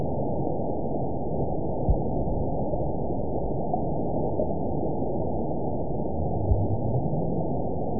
event 921018 date 04/24/24 time 00:36:17 GMT (1 year, 6 months ago) score 9.29 location TSS-AB02 detected by nrw target species NRW annotations +NRW Spectrogram: Frequency (kHz) vs. Time (s) audio not available .wav